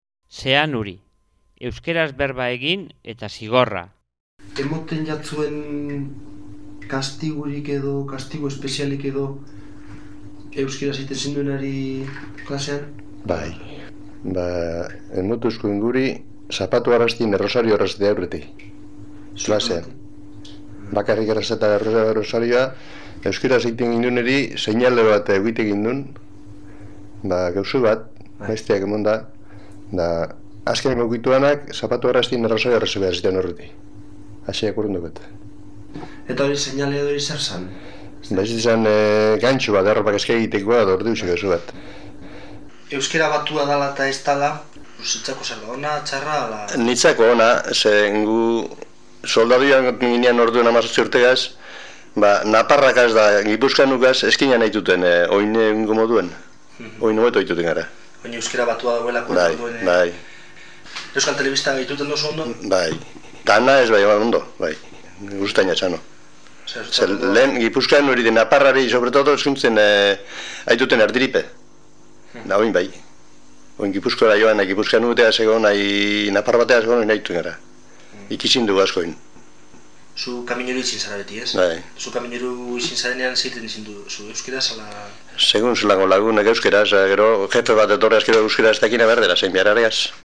1.18. ZEANURI